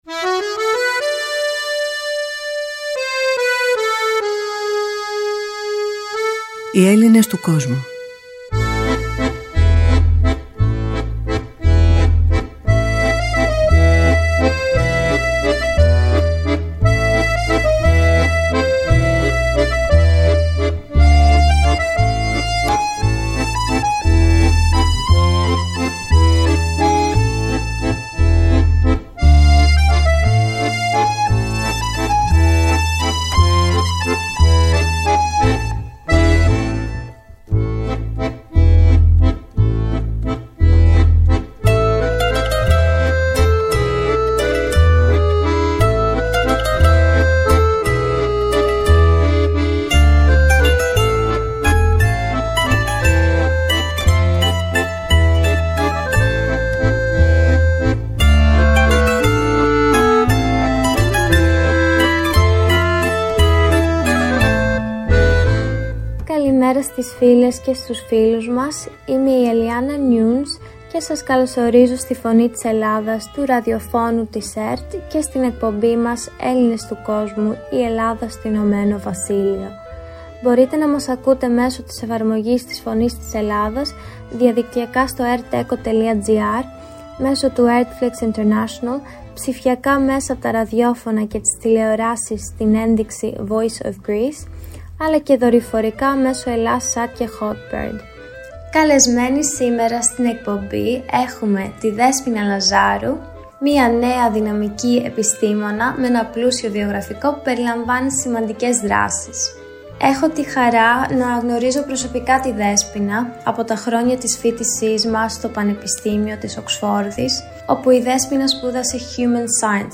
Παρουσίαση